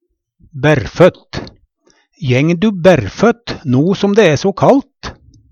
bærrføtt - Numedalsmål (en-US)